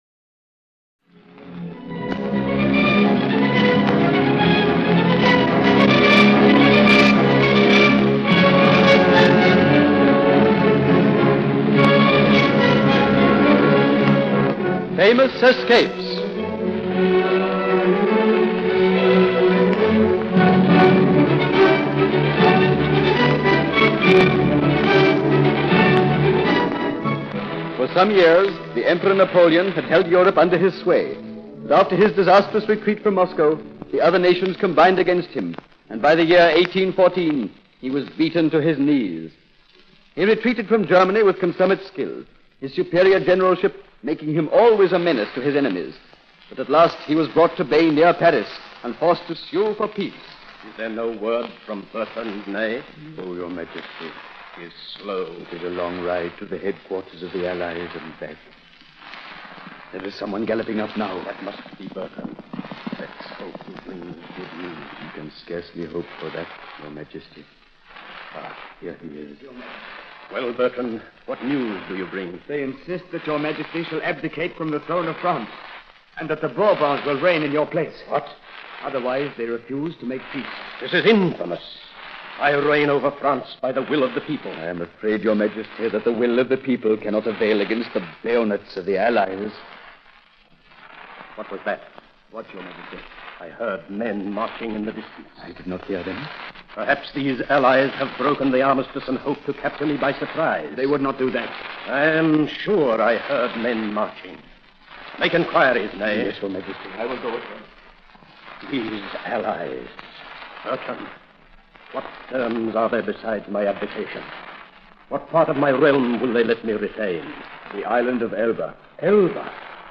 Famous Escapes was a captivating radio series produced in Australia around 1945. The show delved into some of the most daring escapes in history, featuring remarkable characters who managed to break free from seemingly impossible situations.